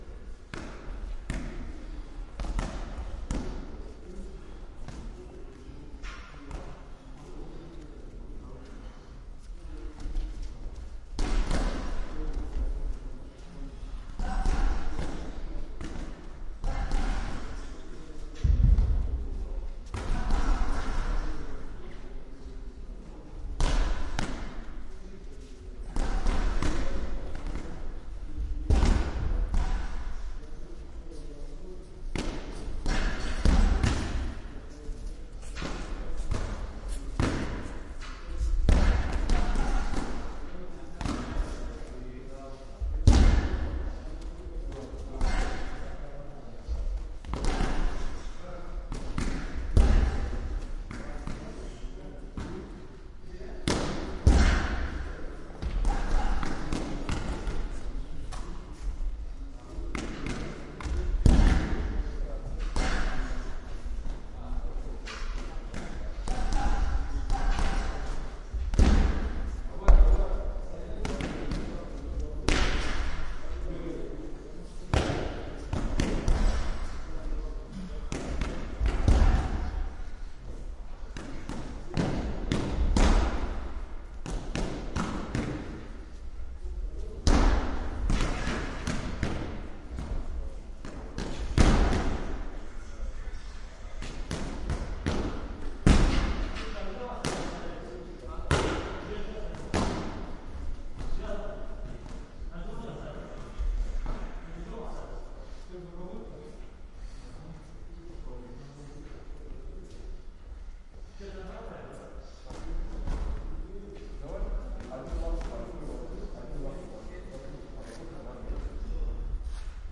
健身房的杠铃声
描述：健身房的杠铃声。用Zoom H2n 录音。
标签： 杠铃 健身房 训练 运动
声道立体声